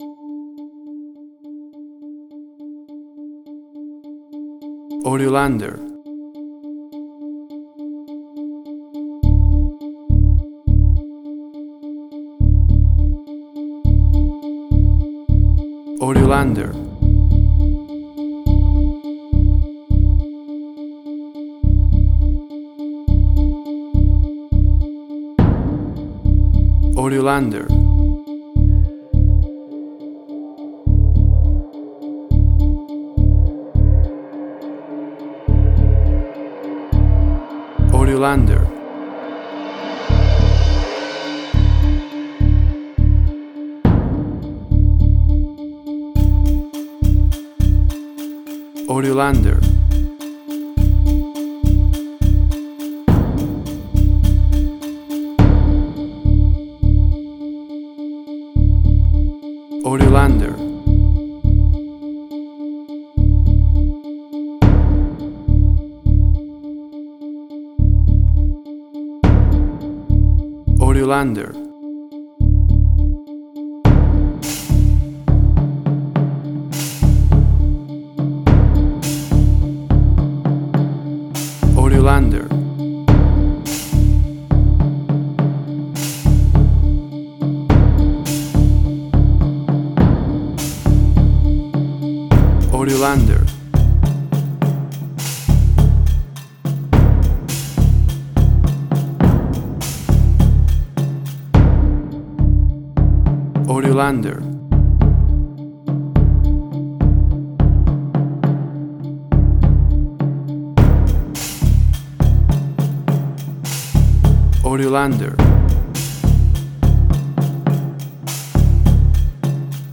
Suspense, Drama, Quirky, Emotional.
Tempo (BPM): 104